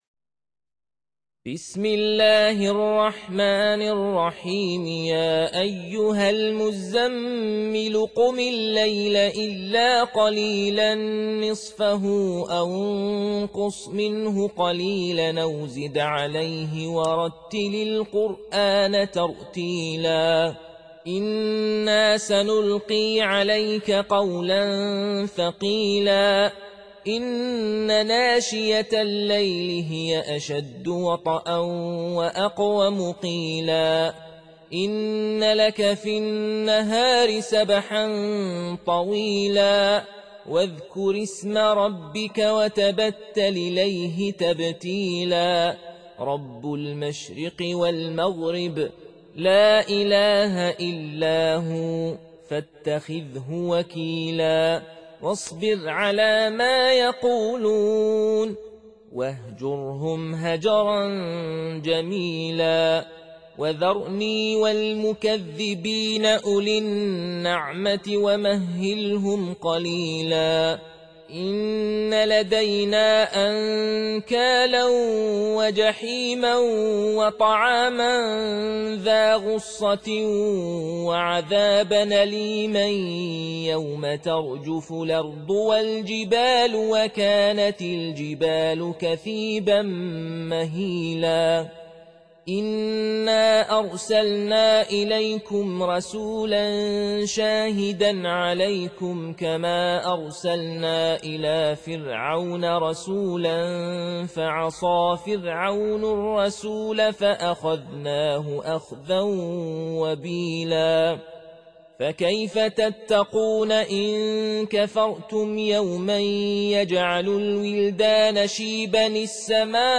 Surah Sequence تتابع السورة Download Surah حمّل السورة Reciting Murattalah Audio for 73. Surah Al-Muzzammil سورة المزّمّل N.B *Surah Includes Al-Basmalah Reciters Sequents تتابع التلاوات Reciters Repeats تكرار التلاوات